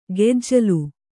♪ gejjalu